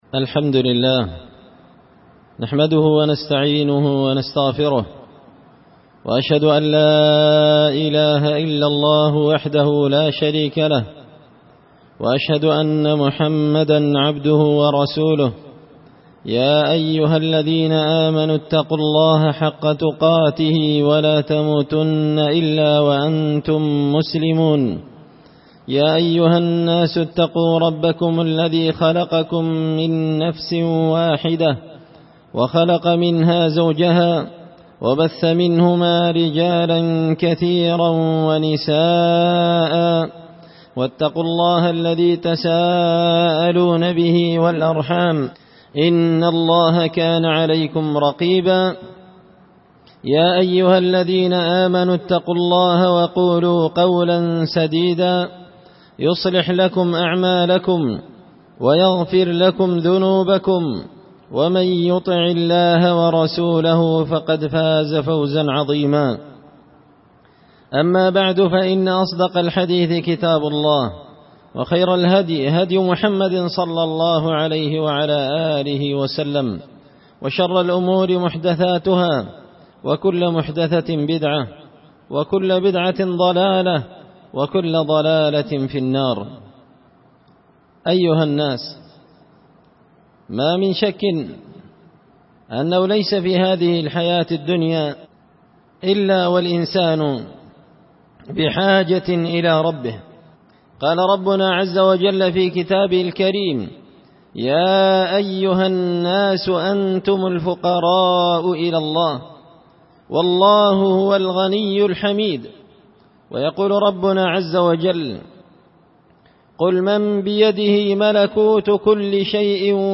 خطبة جمعة بعنوان
دار الحديث بمسجد الفرقان ـ قشن ـ المهرة ـ اليمن